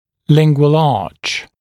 [‘lɪŋgwəl ɑːʧ][‘лингуэл а:ч]лингвальная дуга (при усилении опоры)